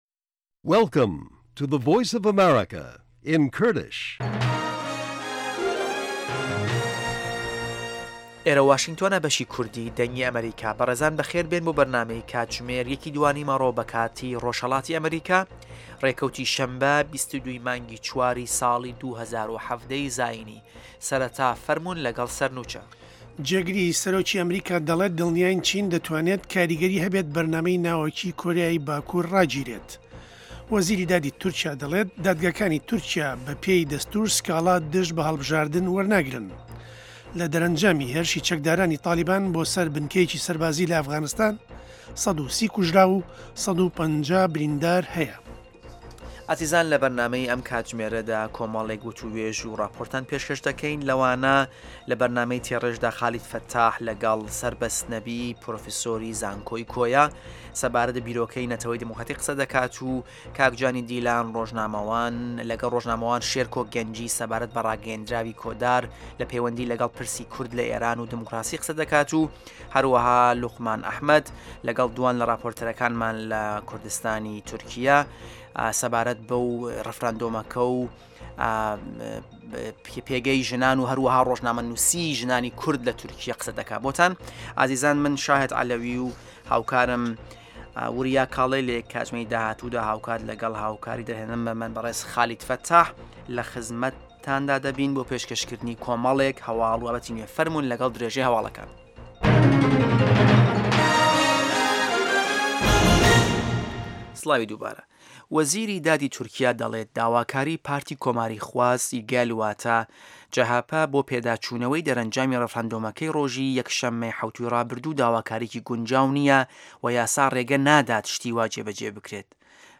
هه‌واڵه‌کان، ڕاپـۆرت، وتووێژ، سه‌رگوتاری ڕۆژانه‌‌ که‌ تیایدا ڕاوبۆچوونی حکومه‌تی ئه‌مه‌ریکا ده‌خرێته‌ ڕوو.